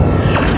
sonda_lancio.wav